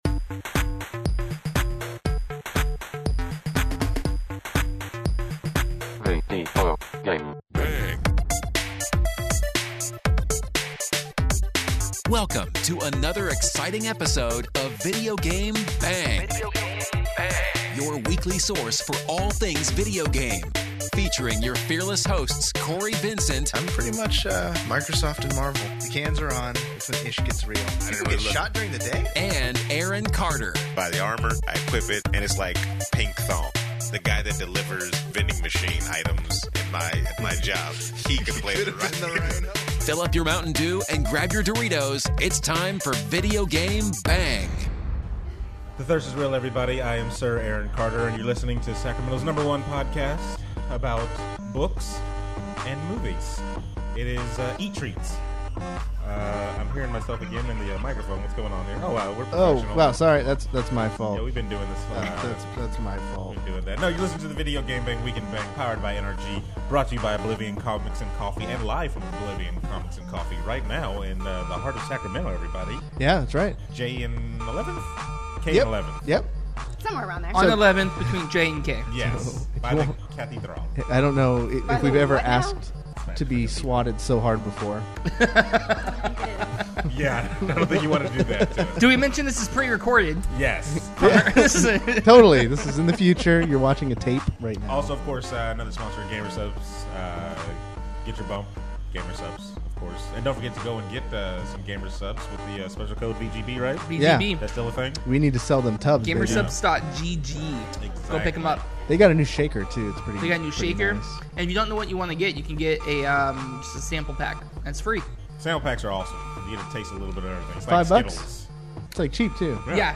The VGB crew is LIVE at Oblivion Comics and Coffee in honor of free comic book day and the 1 year anniversary of Sacramento's #1 spot for comics and coffee.